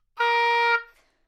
双簧管单音（演奏得不好） " 双簧管 A4 丰富性不好太多簧片
描述：在巴塞罗那Universitat Pompeu Fabra音乐技术集团的goodsounds.org项目的背景下录制。单音乐器声音的Goodsound数据集。
Tag: 好声音 单注 多样本 Asharp4 纽曼-U87 双簧管